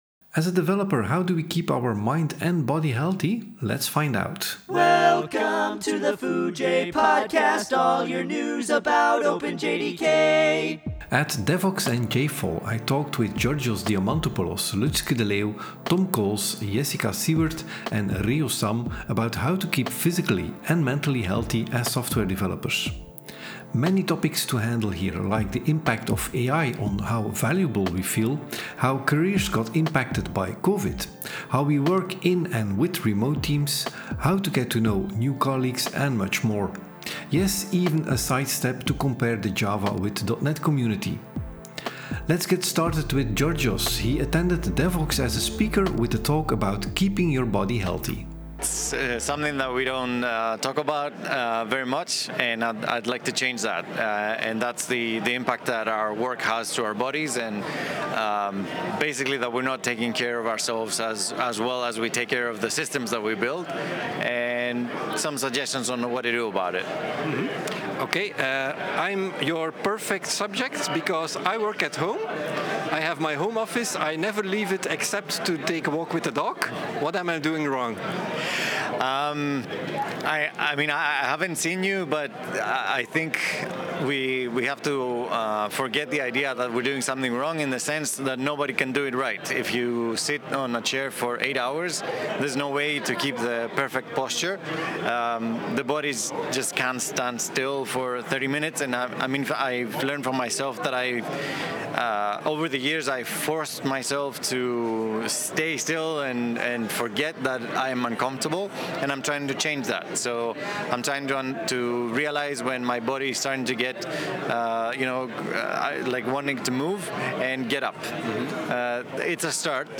1 Proud Of Belgium: Devoxx, JobRunr, Timefold, OpenJDK Mobile, OpenJFX, Thymeleaf, htmx (#60) 35:38 Play Pause 12d ago 35:38 Play Pause Play later Play later Lists Like Liked 35:38 Belgium might be tiny, but we have a strong Java Community! As I was doing interviews at Devoxx in October, I met several of these people, and we talked about their projects, how you can get involved in OpenJDK, and maybe even start a company out of it.